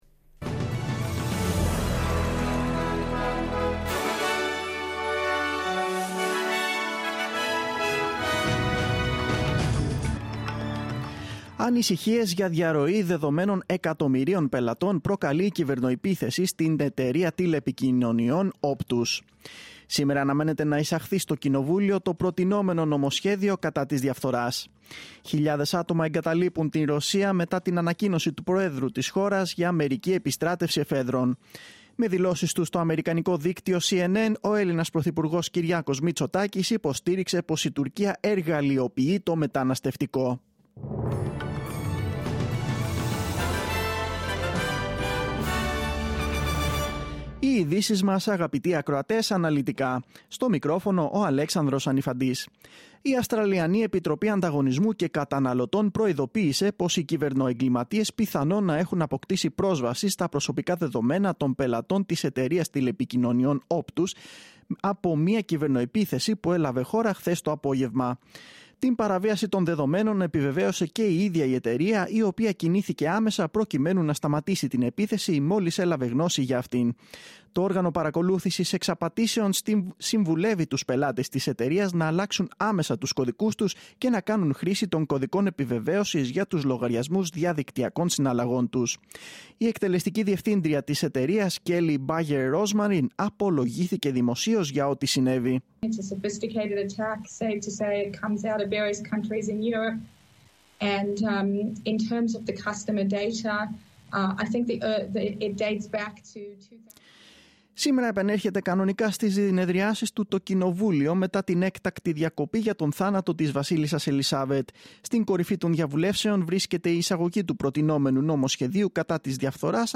News in Greek. Source: SBS / SBS Radio